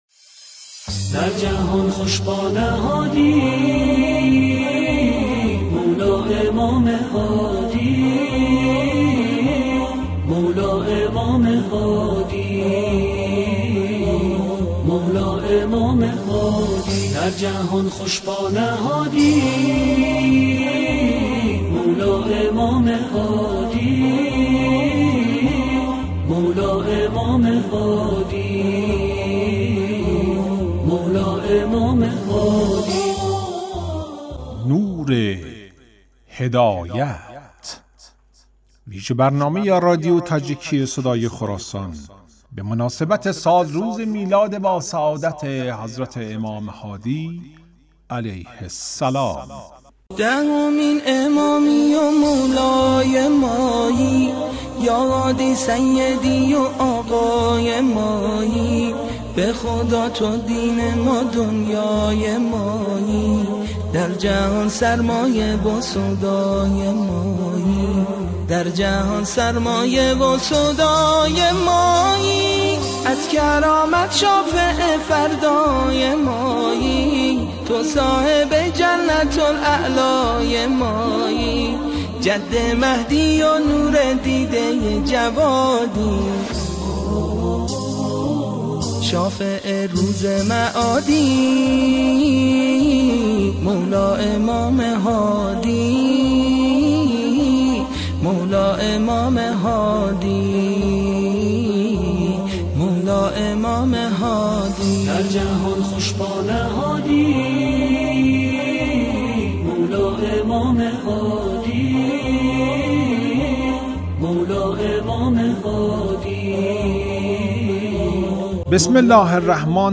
"نور هدایت" ویژه برنامه ای است که به مناسبت سالروز ولادت امام علی النقی الهادی علیه السلام از رادیو تاجیکی پخش شده است.